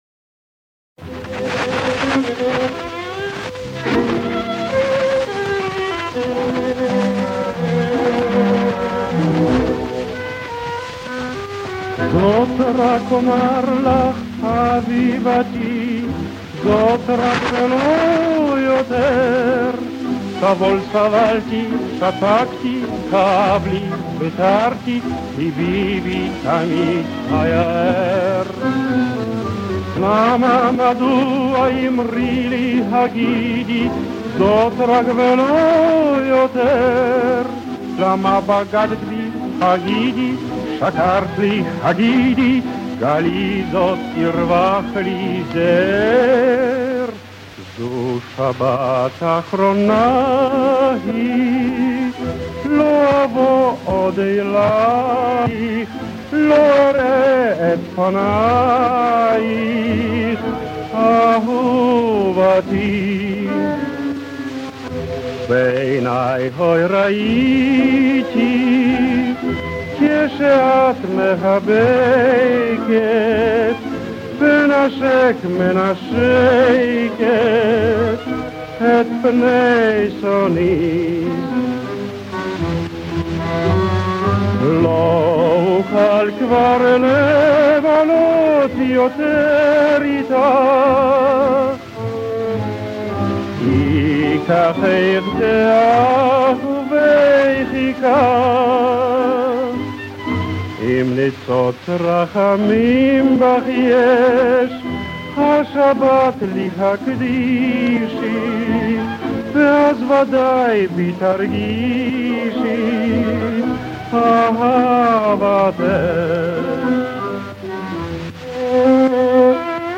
Качество не очень, но-что есть.